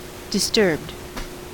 I Disturbed (IPA: dɪˡstɜːbd,
En-us-disturbed.ogg.mp3